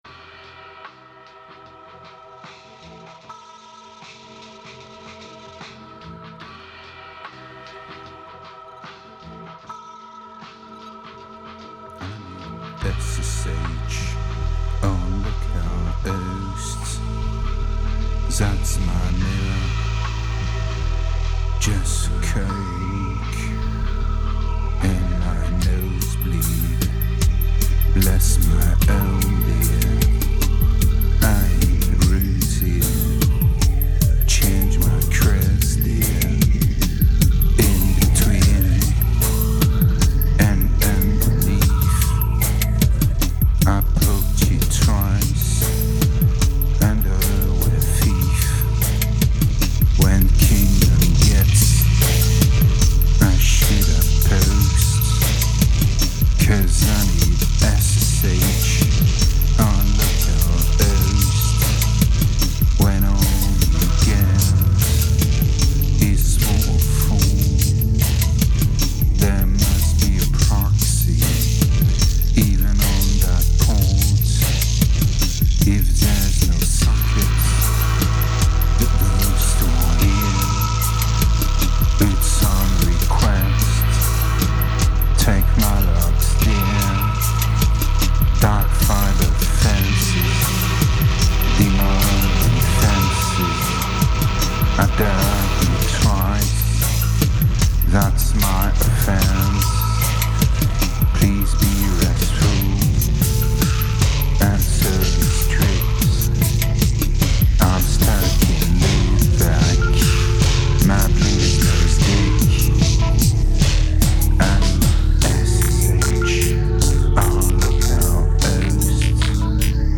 2196📈 - -23%🤔 - 150BPM🔊 - 2009-01-17📅 - -281🌟